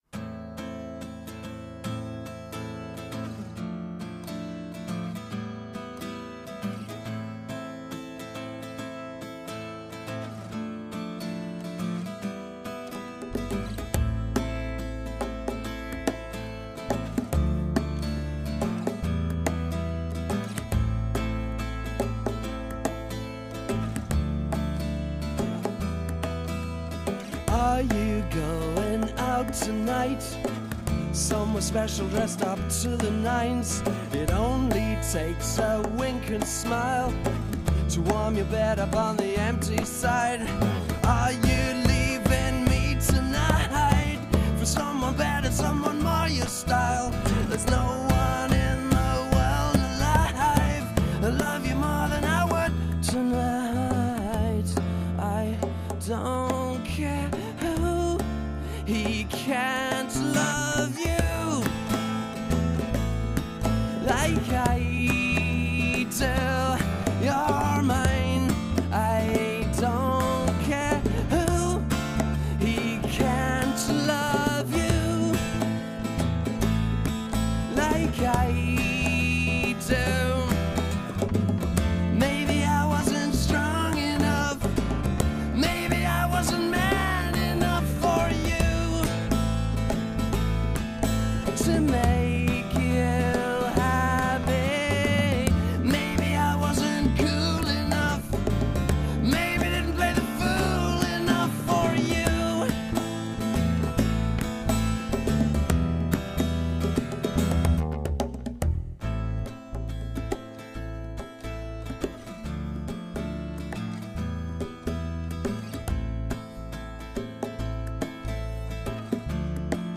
A small Test conducted with a lullabys song